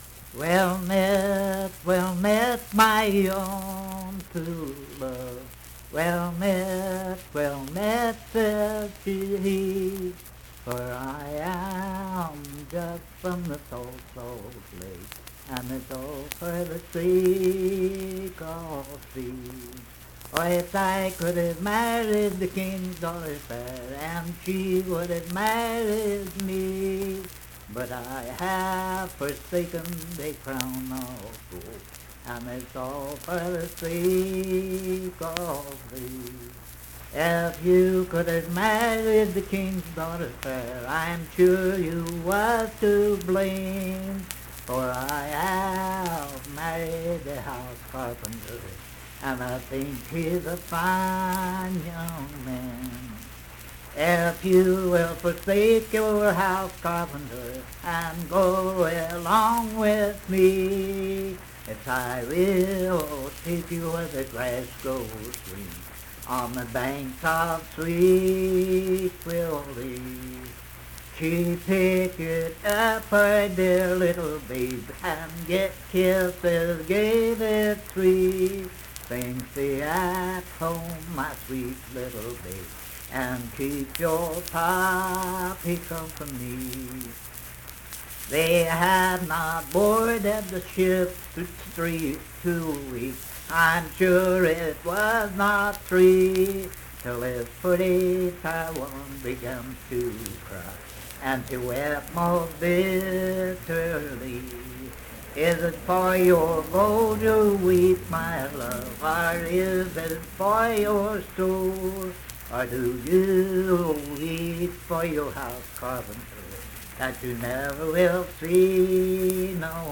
Unaccompanied vocal music
Verse-refrain 10(4).
Voice (sung)